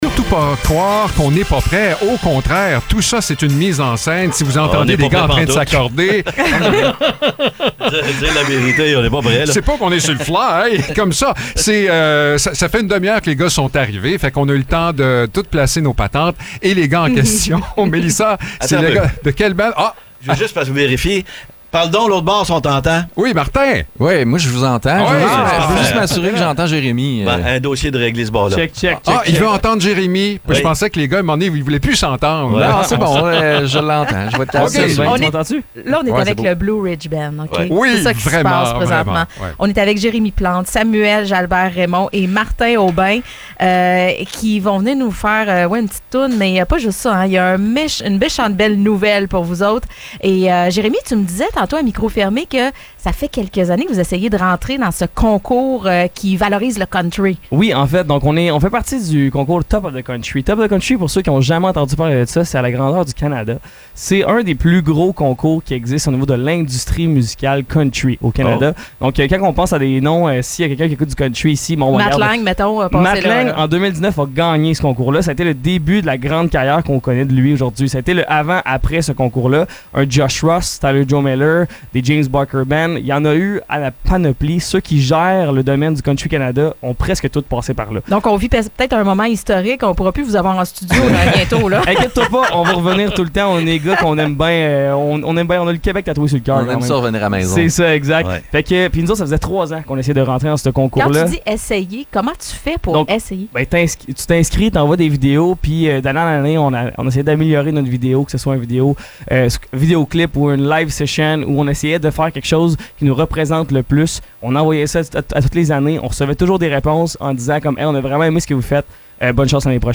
Le Blue Ridge Band débarque en studio
On sent toute la fébrilité du trio, qui vient de franchir une étape majeure en se hissant parmi les demi-finalistes du prestigieux concours Top of the Country.